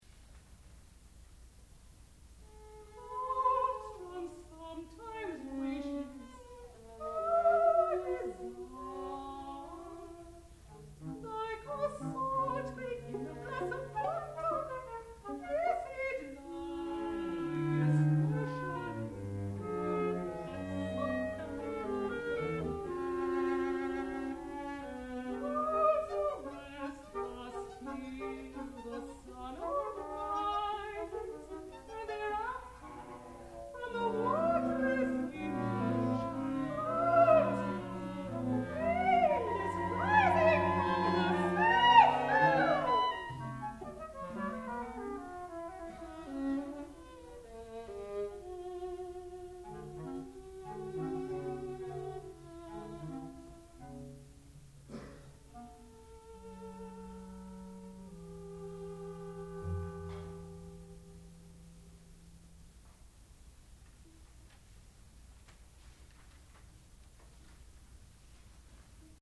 sound recording-musical
classical music
Cambiata Soloists (performer).